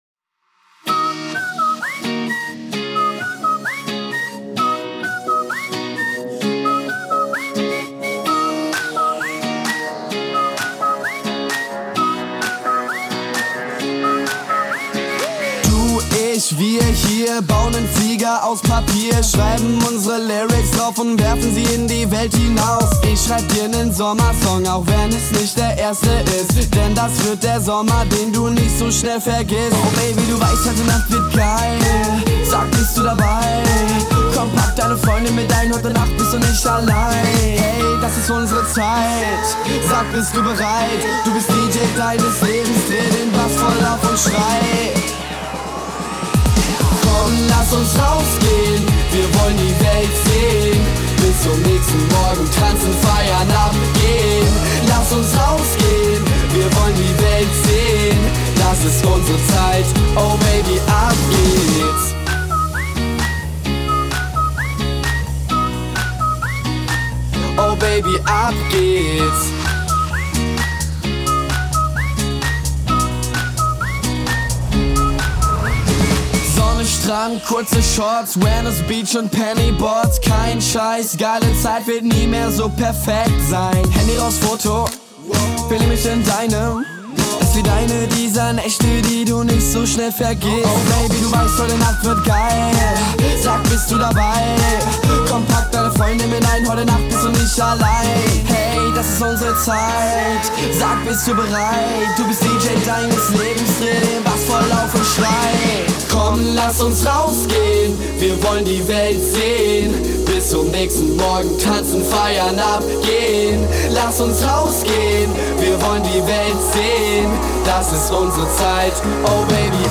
DEMO und Playback hab ich euch dazu gepackt!